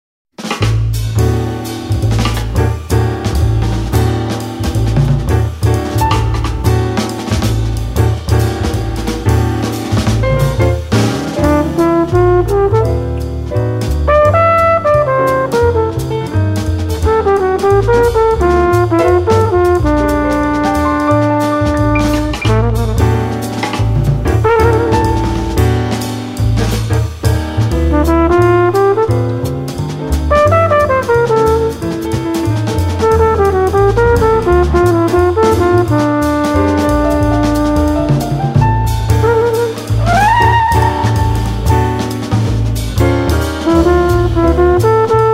Compilation Jazz Album